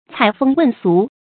采風問俗 注音： ㄘㄞˇ ㄈㄥ ㄨㄣˋ ㄙㄨˊ 讀音讀法： 意思解釋： 風：歌謠；俗：風俗。采集歌謠，訪問風俗 出處典故： 清 俞蛟《夢廠雜著 潮嘉風月》：「 采風問俗 ，紀載宜詳；品翠題紅，篇章爭麗。」